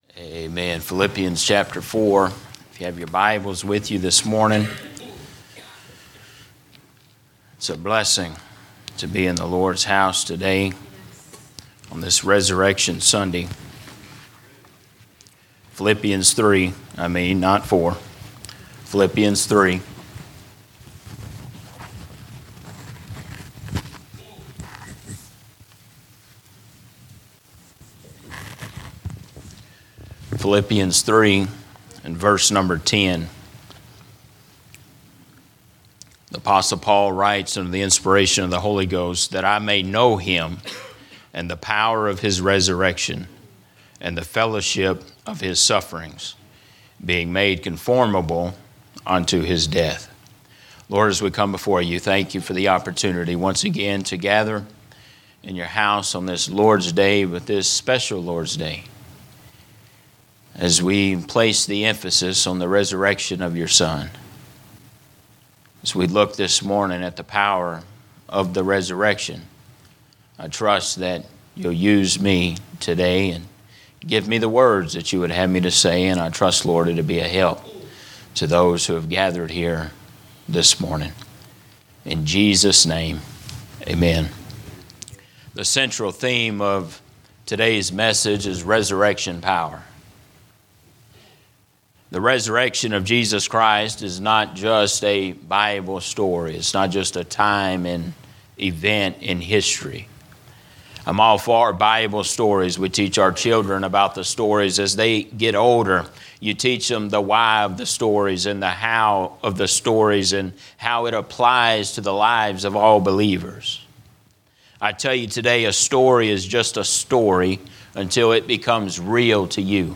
Sermons - Emmanuel Baptist Church